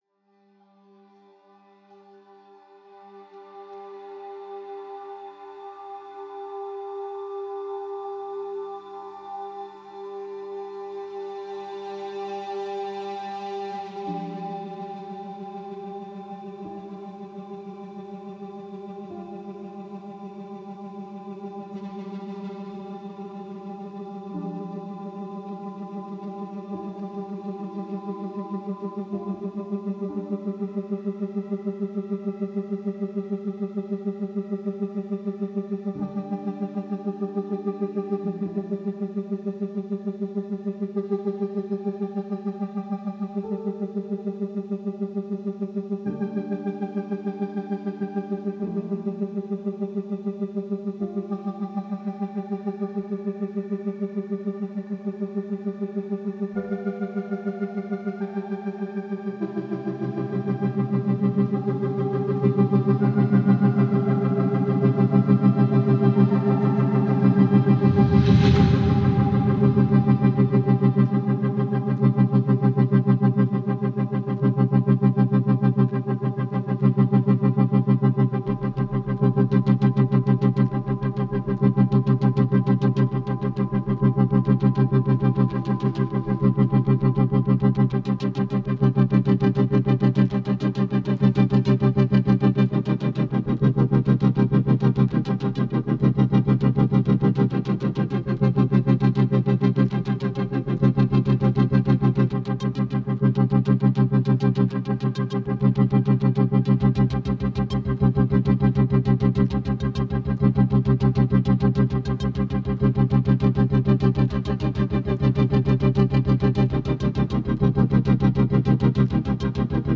multichannel loudspeaker system in Berlin